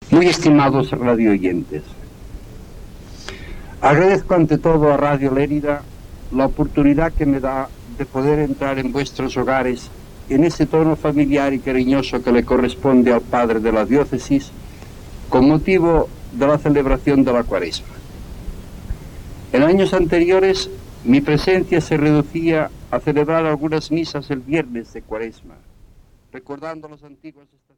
Paraules del bisbe de Lleida Ramon Malla amb motiu de la Quaresma